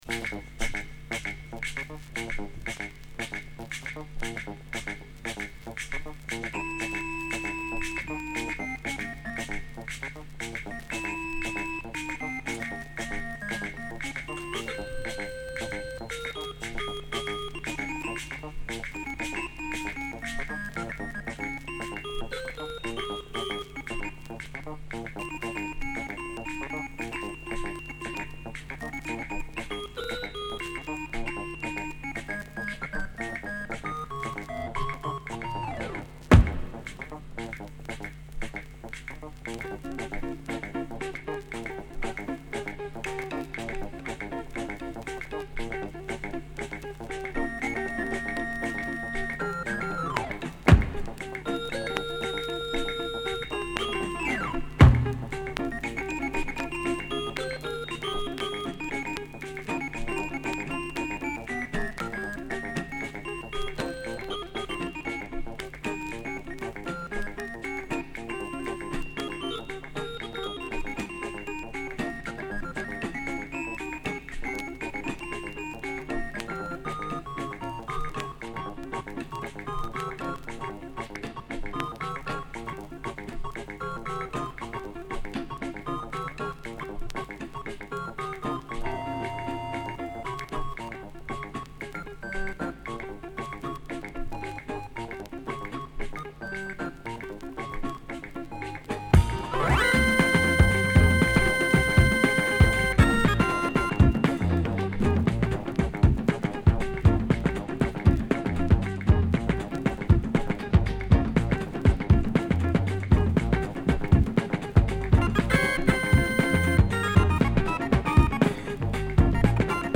シェケレ／ギターにエレピが絡むイントロから
アフロドラムの上でホーン隊とエレピが絡むクールな